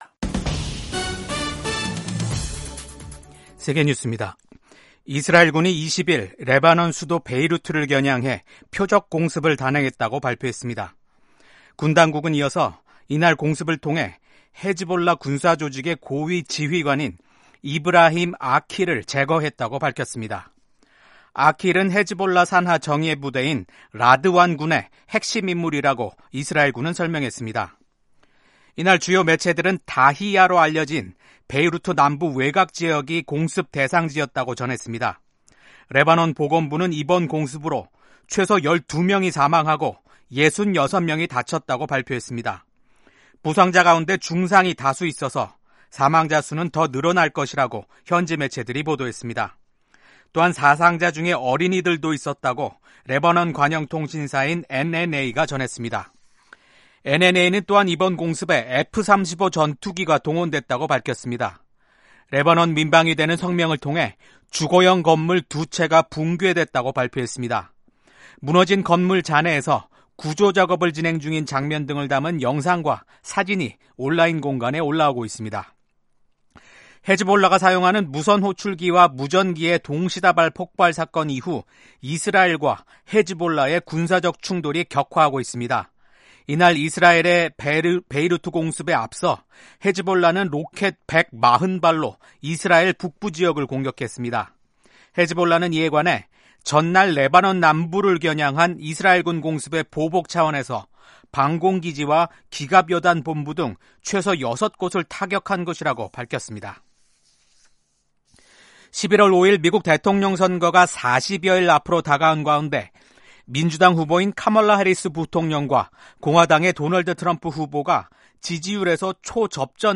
세계 뉴스와 함께 미국의 모든 것을 소개하는 '생방송 여기는 워싱턴입니다', 2024년 9월 21일 아침 방송입니다. 이슬람 무장 조직 헤즈볼라가 이스라엘을 상대로 한 보복을 예고한 가운데 이스라엘군이 레바논 내 헤즈볼라 목표물들을 공격했습니다. 미국 공화당 대선 후보인 도널드 트럼프 전 대통령이 유대계 미국인들에게 지지를 호소했습니다.